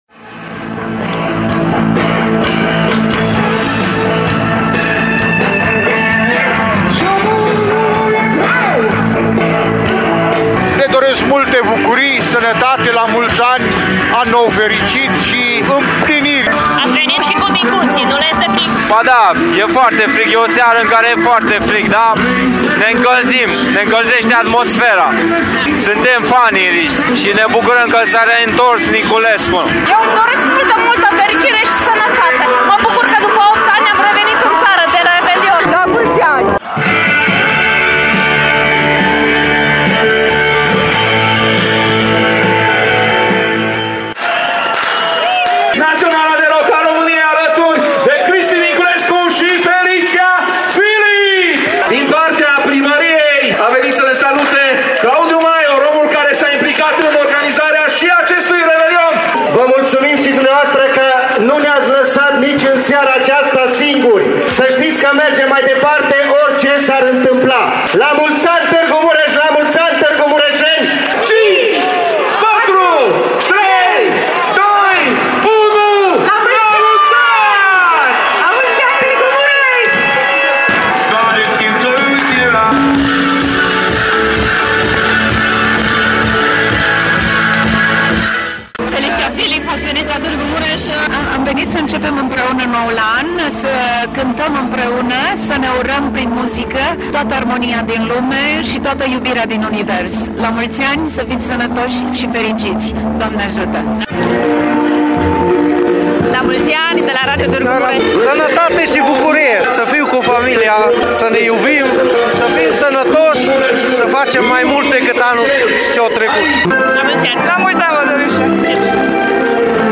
Chiar dacă mercurul din termometre arăta -10 grade Celsius, târgumureșenii au venit cu mic cu mare să sărbătorească în stradă intrarea în noul an.
Imediat după ora 12 a avut loc un spectaculos foc de artificii, ce a durat 6 minute, după care a urmat tradiționalul vals: